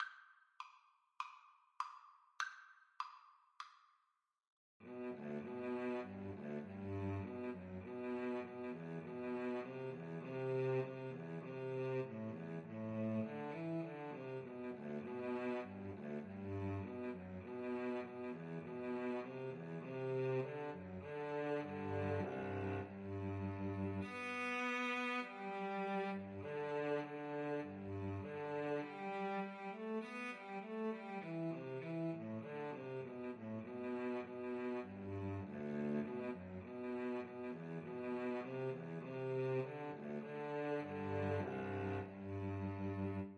Free Sheet music for Cello Duet
"Simple Gifts" is a Shaker song written and composed in 1848 by Elder Joseph Brackett.
G major (Sounding Pitch) (View more G major Music for Cello Duet )
4/4 (View more 4/4 Music)
Traditional (View more Traditional Cello Duet Music)